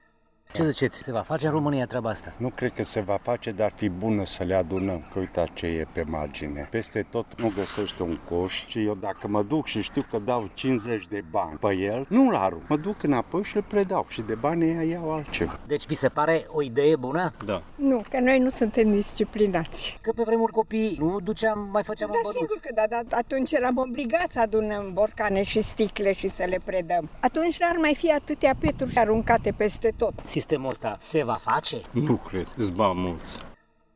Mureșenii sunt de acord că ideea de retur a ambalajelor la băuturi e binevenită, dar sunt reticenți că ea se va și implementa: